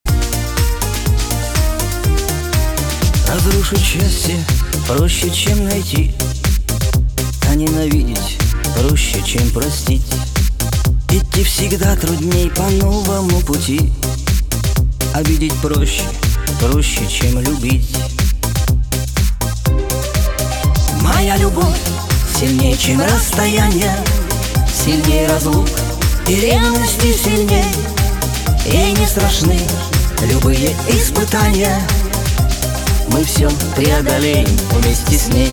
• Качество: 320, Stereo
мужской вокал
дуэт
русский шансон